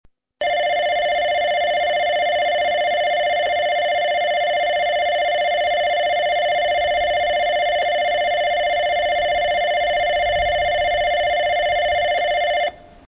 スピーカー：UNI-PEX・SC-10JA（ソノコラム）
スピーカーの位置が高く、海から抜ける風が強い日は収録には不向きでした。
千早駅　放送被り時のベル　(64KB/13秒)
chihaya-bell.mp3